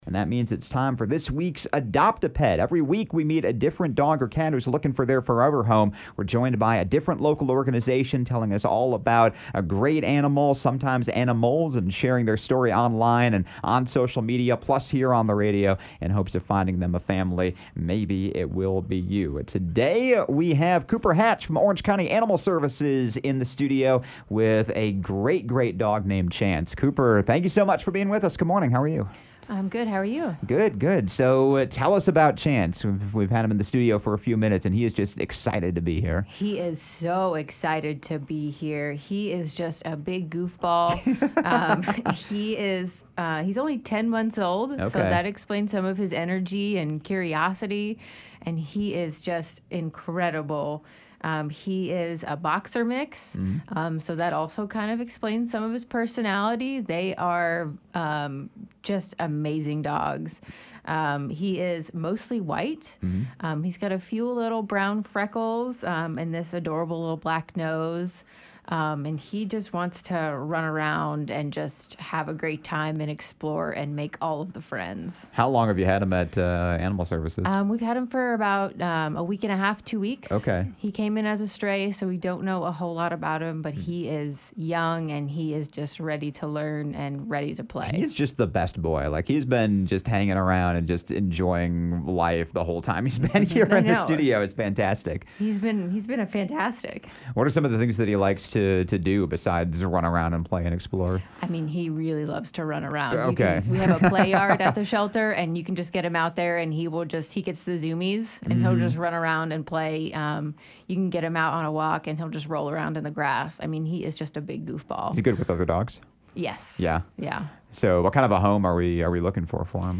on-air segment